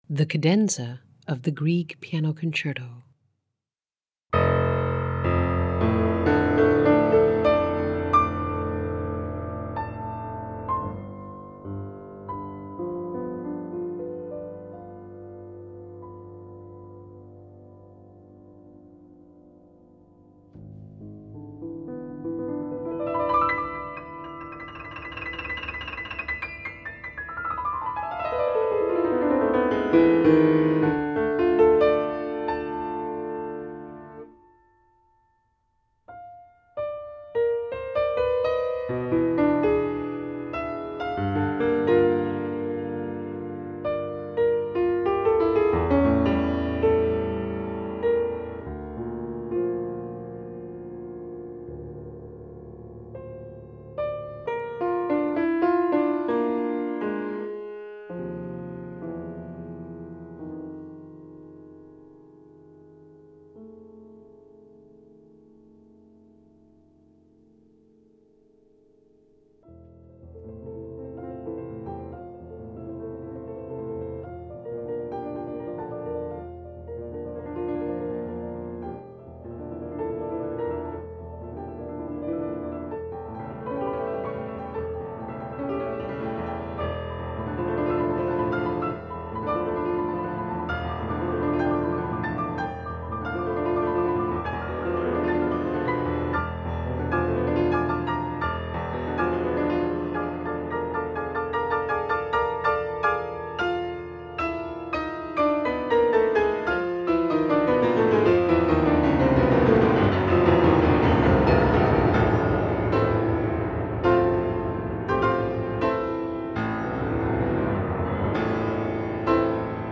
Track 18: Piano Concerto in A Minor